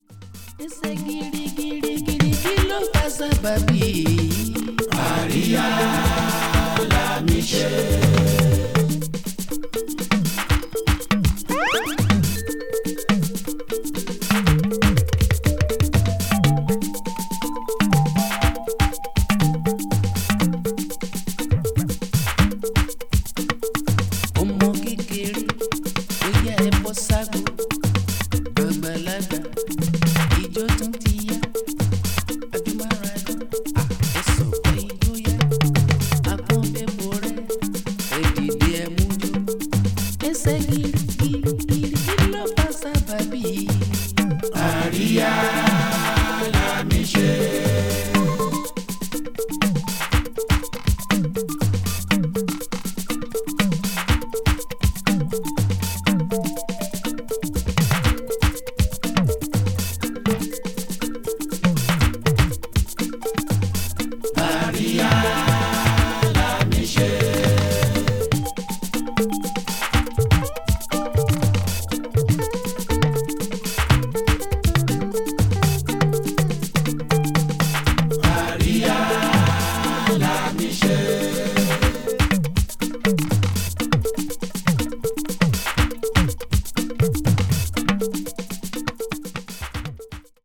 is a prime example of Nigerian juju music.